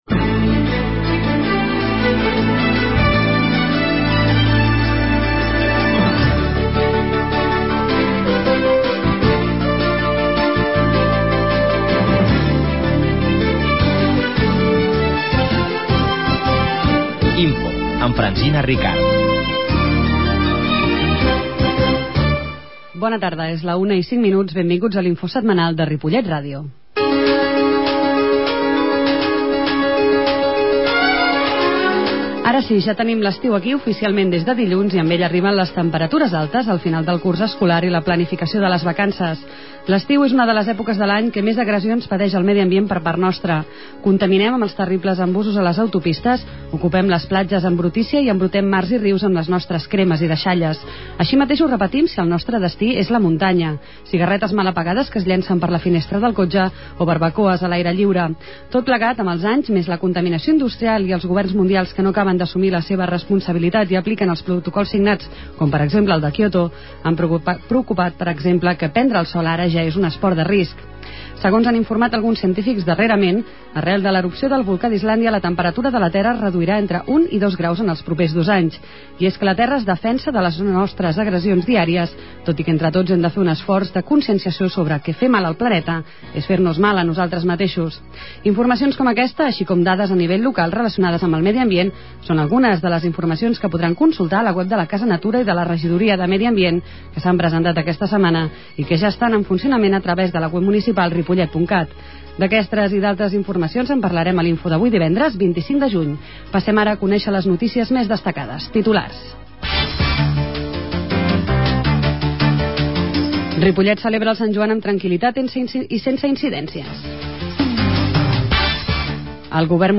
La qualitat de so ha estat redu�da per tal d'agilitzar la seva baixada.